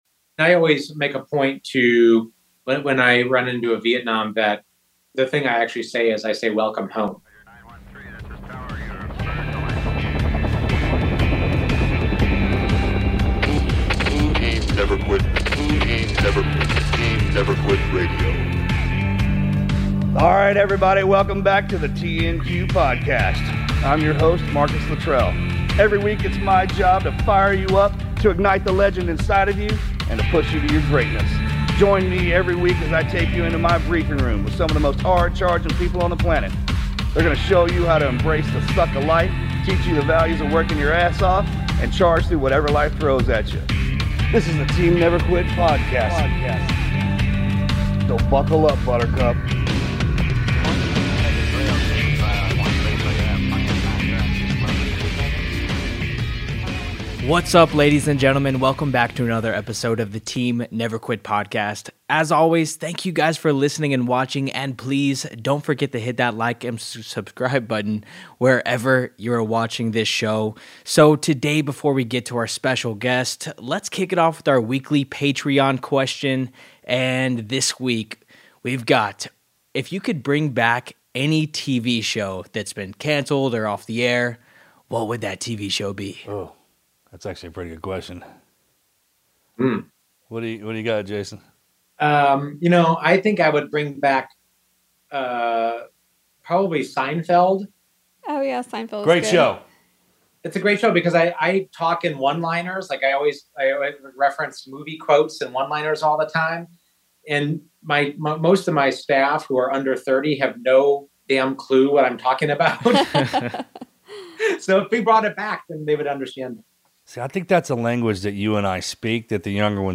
Tune in for a powerful conversation on service, sacrifice, and putting people before politics.